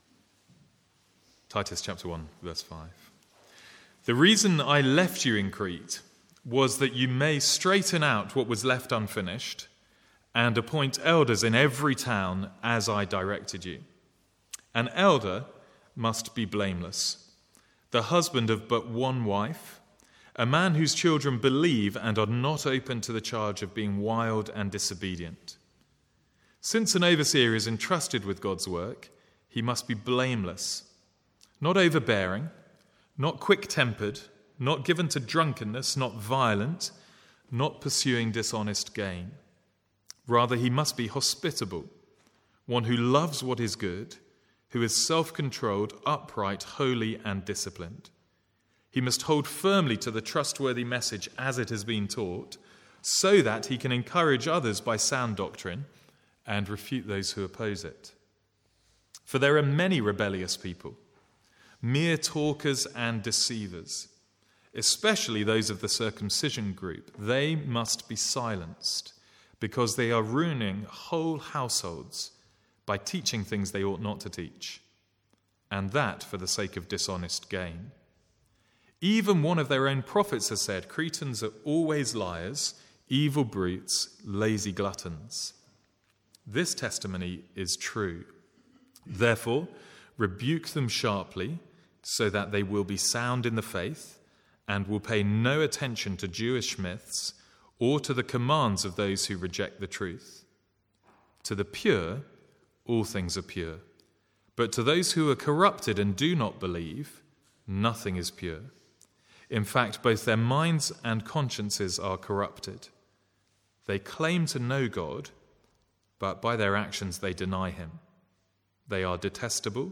From the Sunday morning series in Titus.
Sermon Notes